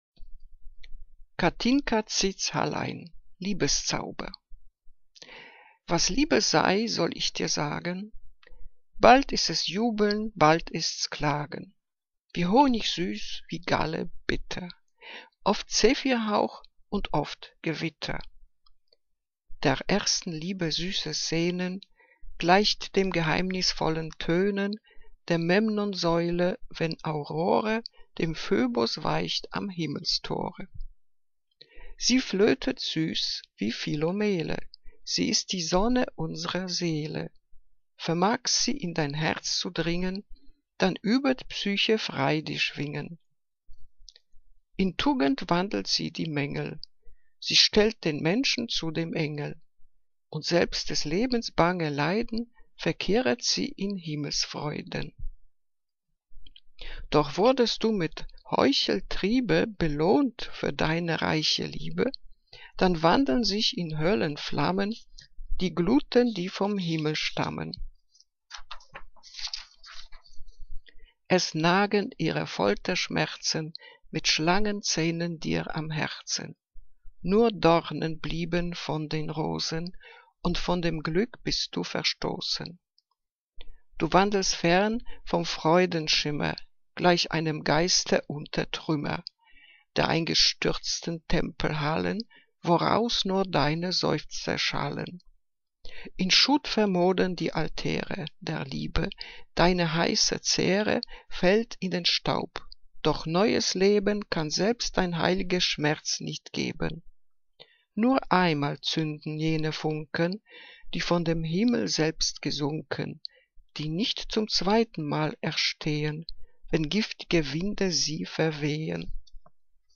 Liebeslyrik deutscher Dichter und Dichterinnen - gesprochen (Kathinka Zitz-Halein)